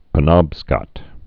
(pə-nŏbskət, -skŏt)